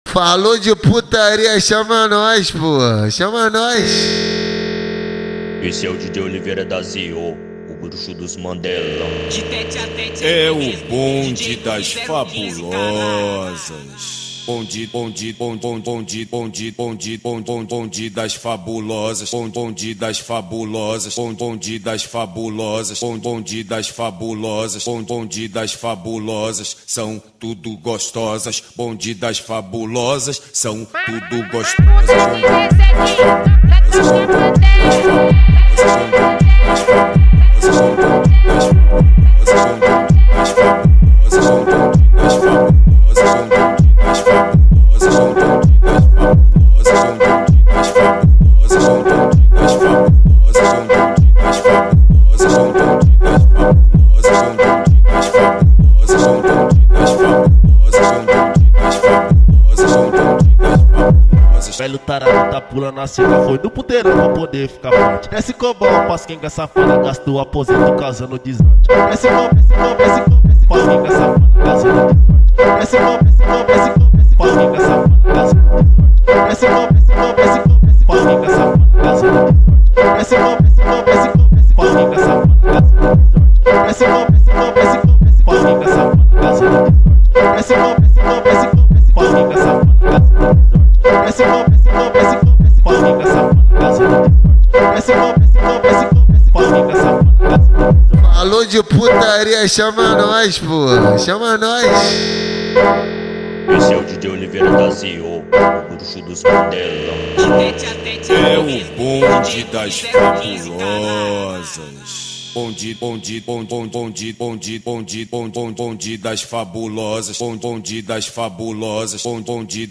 2025-01-01 19:58:29 Gênero: Trap Views